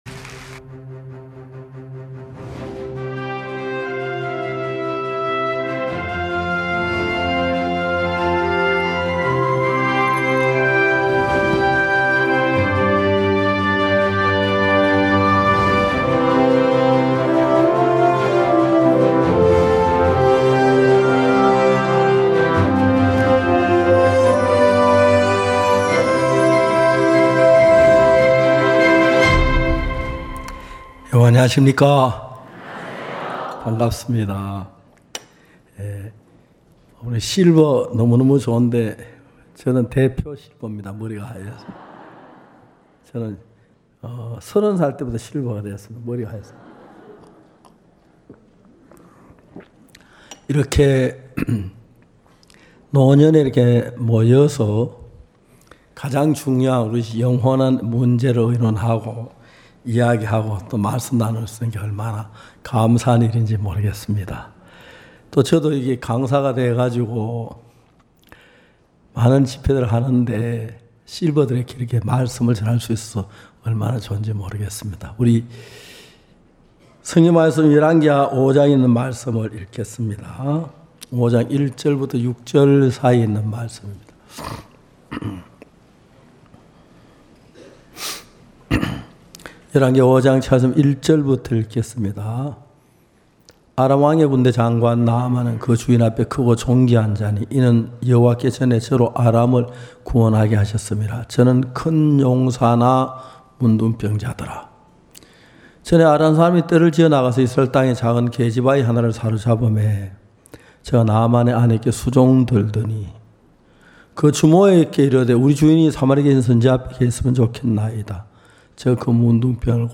매년 굿뉴스티비를 통해 생중계 됐던 기쁜소식 선교회 캠프의 설교 말씀을 들어보세요.